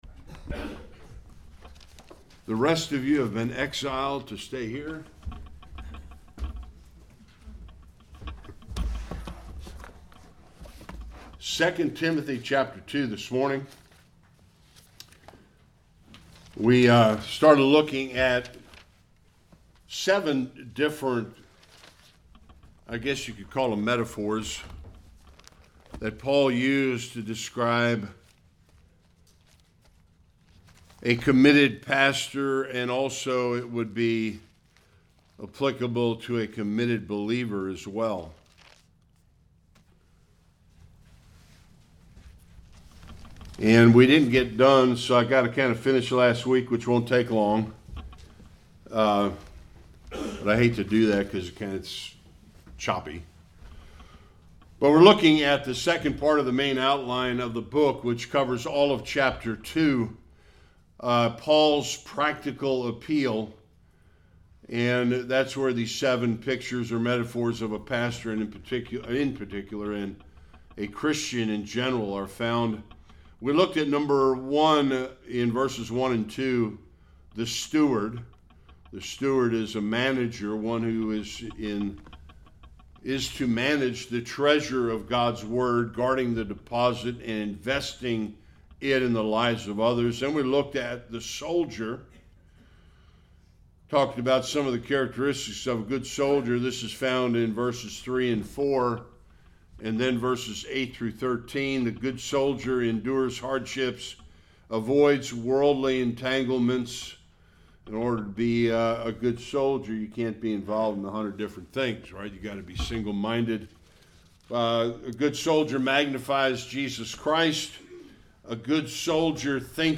5-7 Service Type: Sunday Worship We will look at the next 2 pictures of a pastor